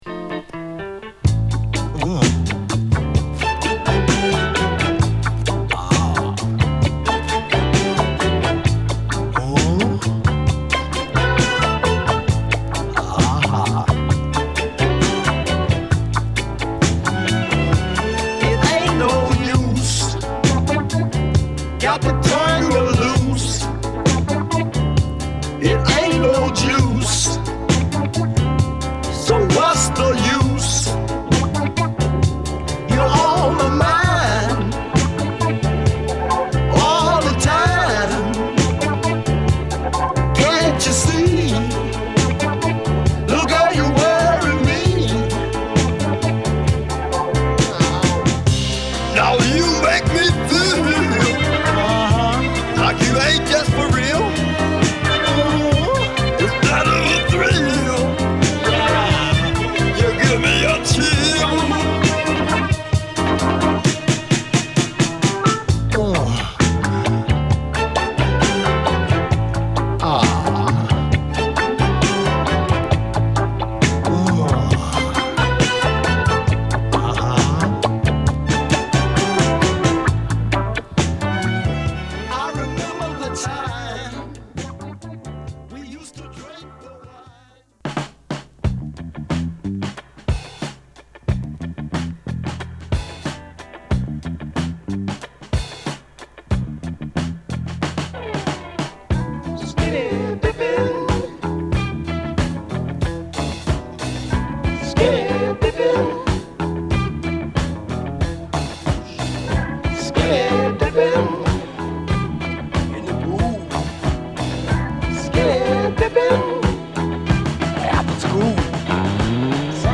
緩やかでリラックスしたサウンドを披露